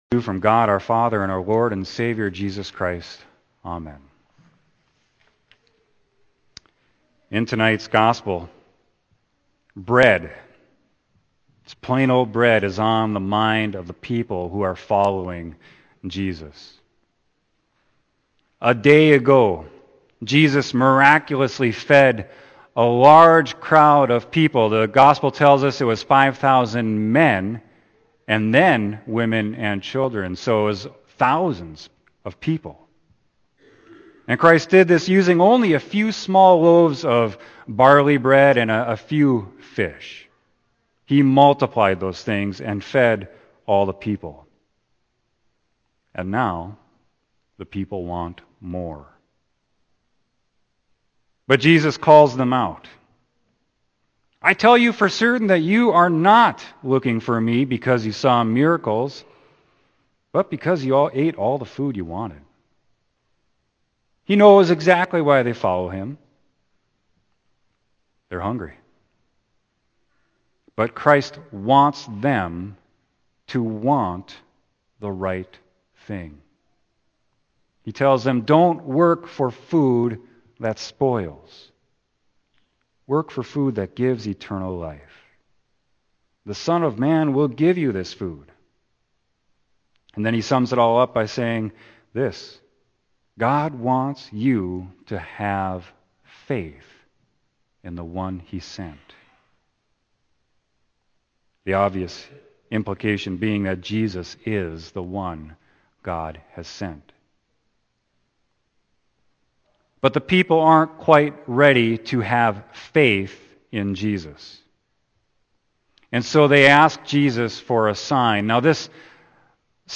Sermon: John 6.25-40